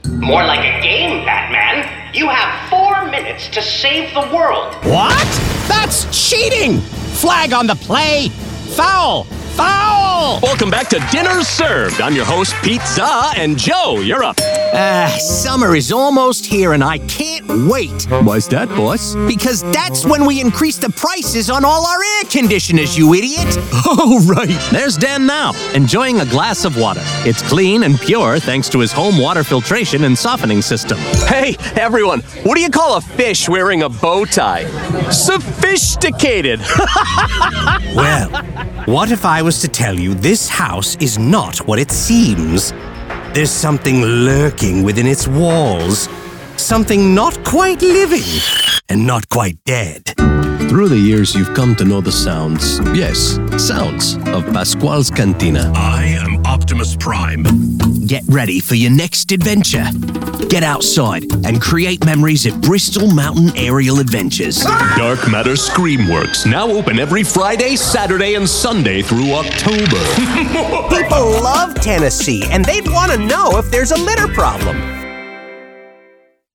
Male
Character, Confident, Corporate, Deep, Friendly, Gravitas, Natural, Wacky, Versatile
General American (native), Southern American, New York American, Boston American
He has a tremendous vocal range and excellent comedic timing.
dry studio read.mp3
Microphone: Sennheiser MKH 416
Audio equipment: Private, Source-Connect Certified studio, featuring wired, business-class gigabit fiber internet, and a professionally treated recording booth.